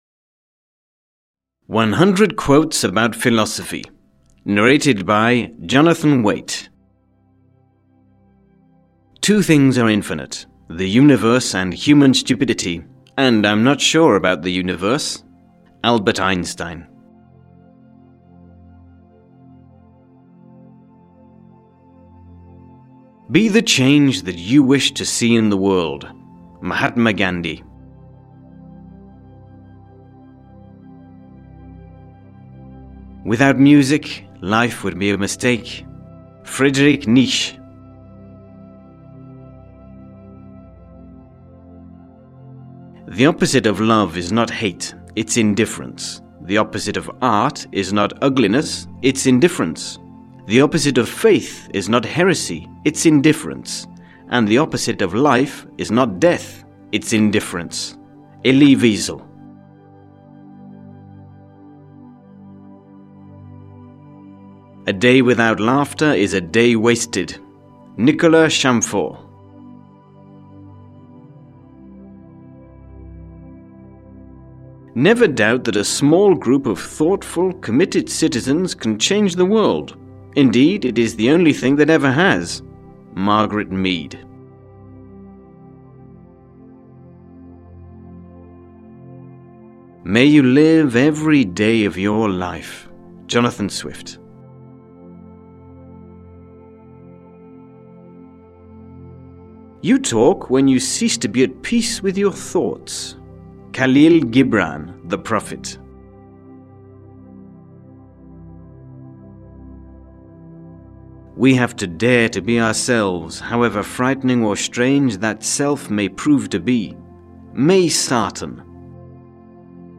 Audio kniha100 Quotes About Philosophy (EN)
Ukázka z knihy